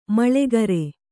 ♪ maḷegare